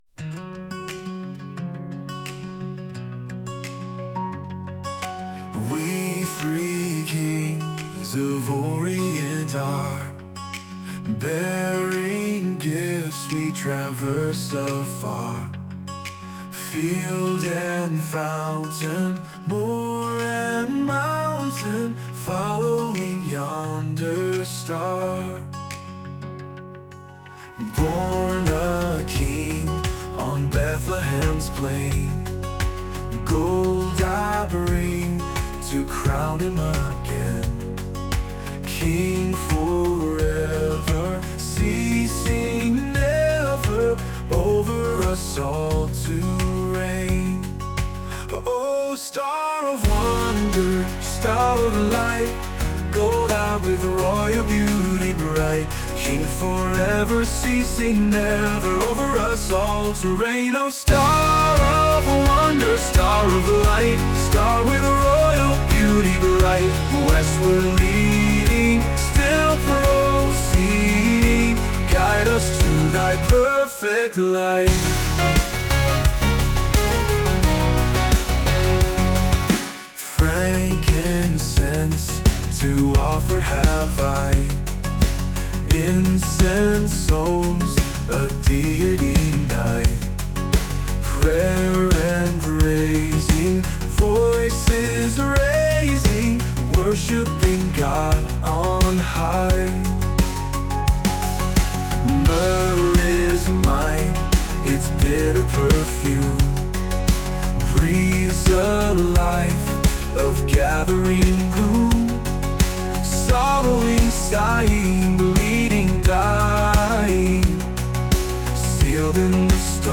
We Three Kings A classic Christmas carol with a spin to it. Originally it was sung in 3/8, but this one will be in 4/4.